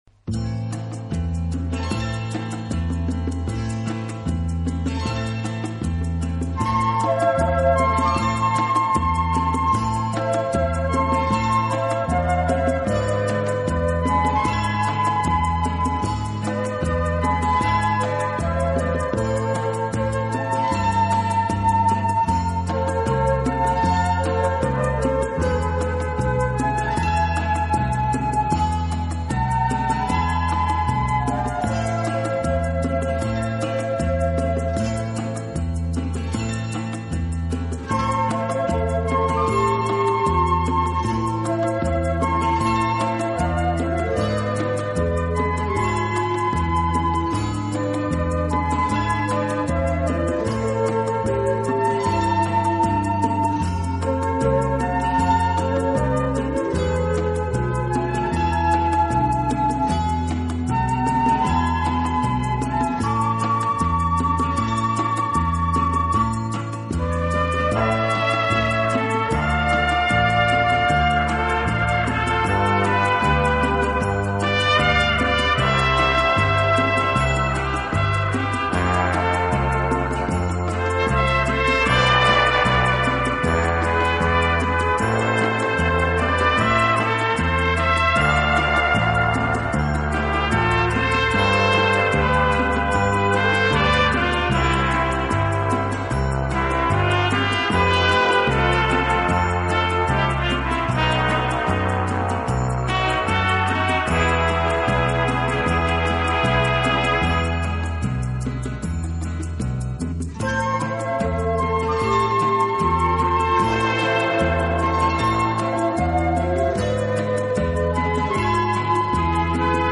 以擅长演奏拉丁美洲音乐而著称。
乐器的演奏，具有拉美音乐独特的韵味。